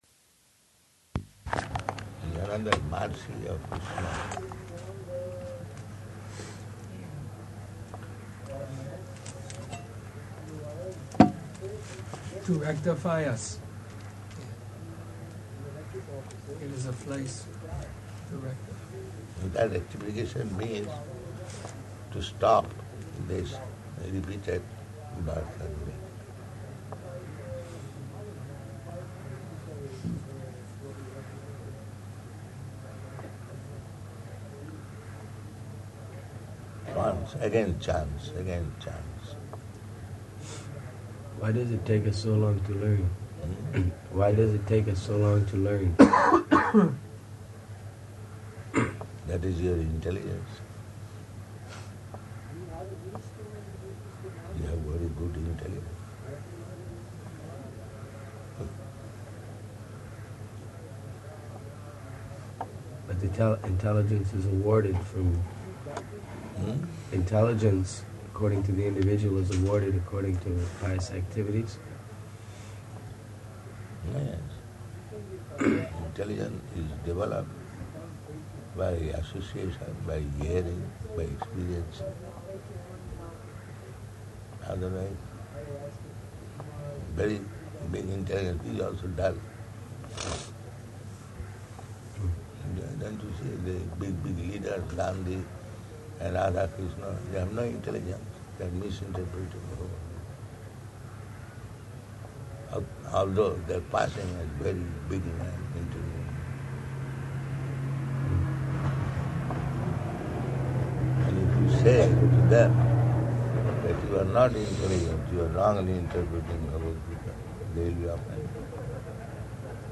Room Conversation
-- Type: Conversation Dated: January 27th 1977 Location: Bhubaneswar Audio file